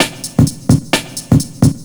TSNRG2 Breakbeat 008.wav